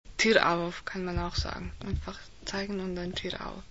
Originalton Lehrerin